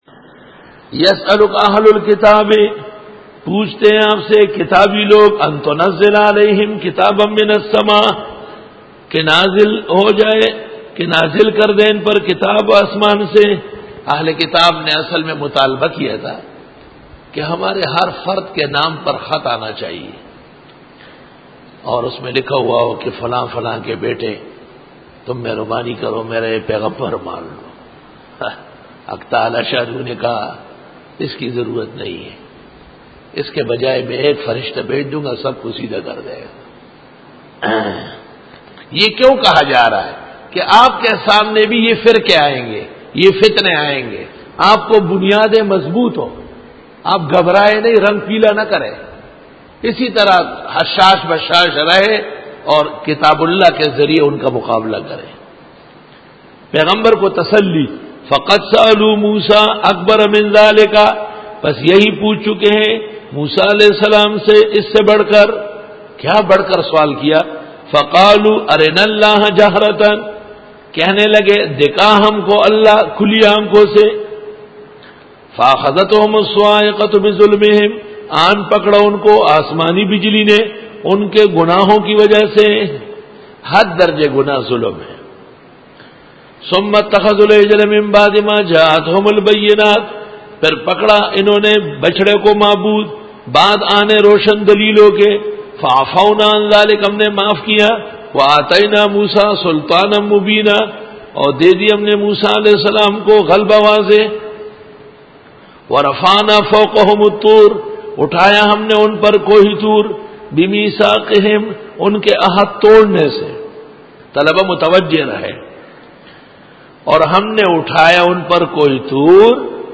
سورۃ النساء-رکوع-22 Bayan